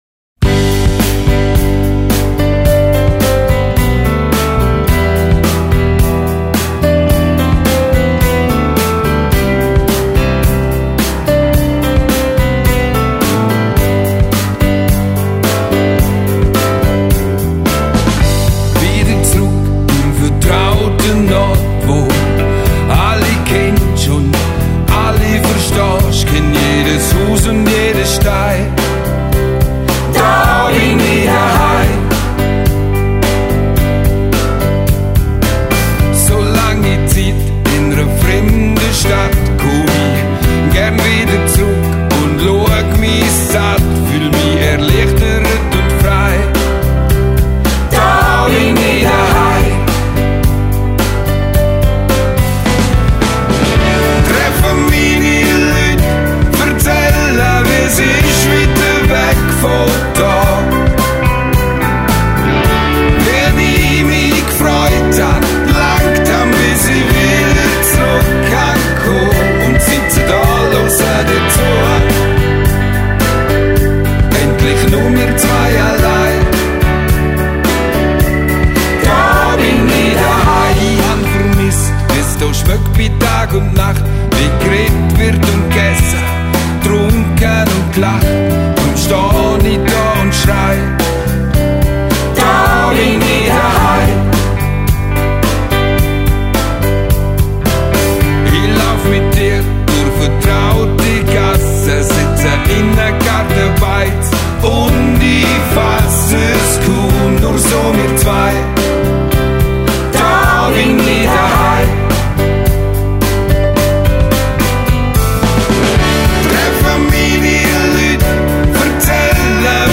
Swiss dialect rock.
Grison dialect